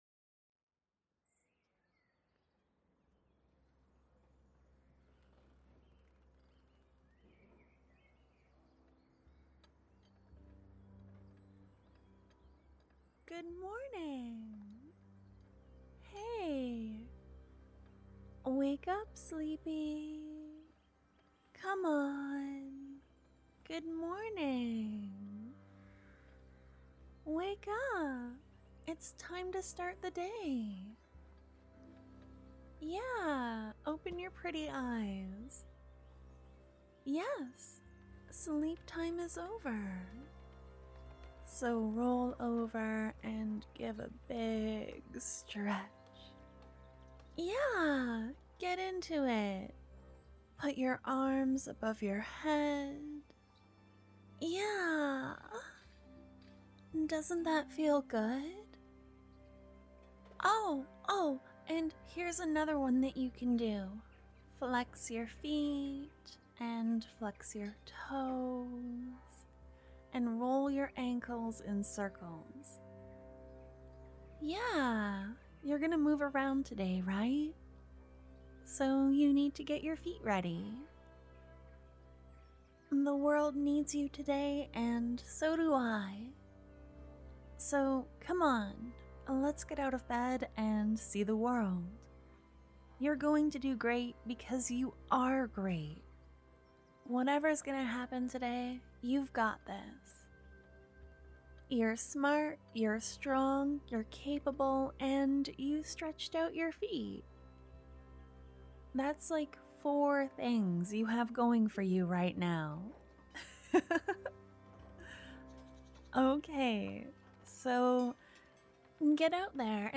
This one is a little more high energy, a cute girl wakes you up and gets you ready for the day with some stretches~!!
Downloads Download Patreon_Cute_Girl_Alarm.mp3 Download Patreon_Cute_Girl_Alarm.mp3 Content Hey guys~!